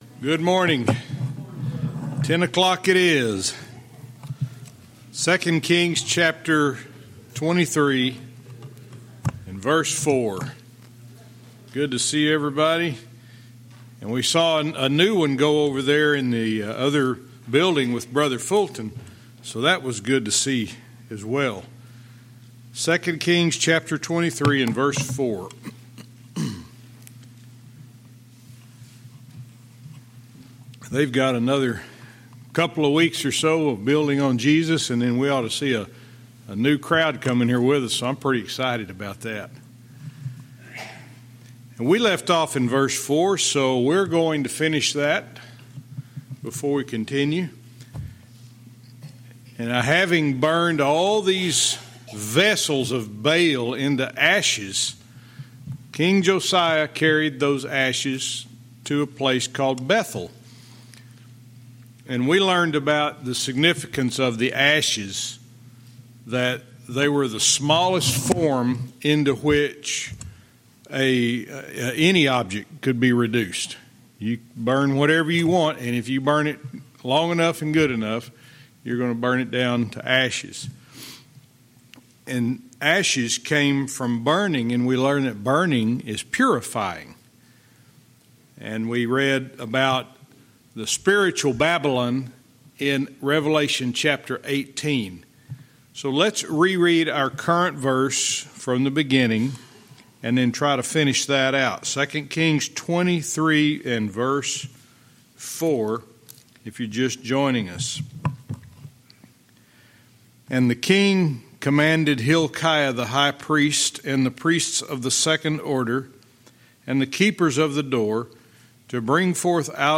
Verse by verse teaching - 2 Kings 23:4-5